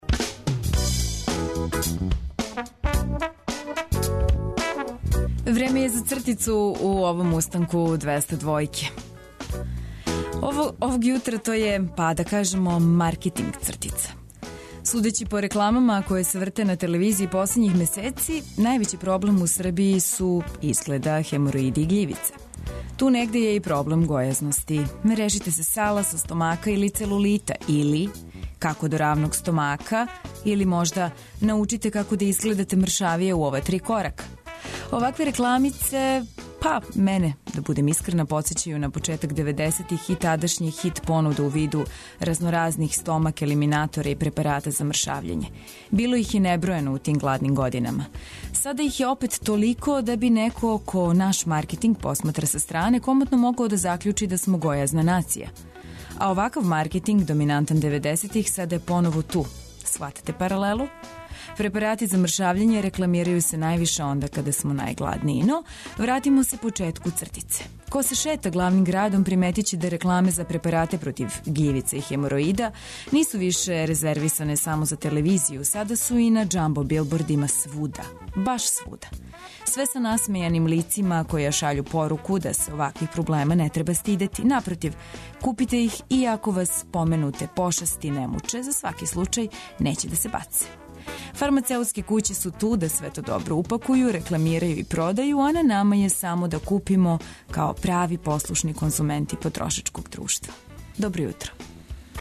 Размрдавамо се уз добру музику, разбуђујемо уз нове вести и информације које су потребне у новом радном дану. Наравно, све то уз стандардне рубрике у нашем јутарњем програму.